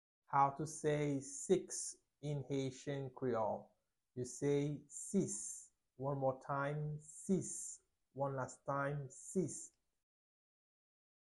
Pronunciation:
9.How-to-say-Six-in-haitian-creole-–-Sis-pronunciation-.mp3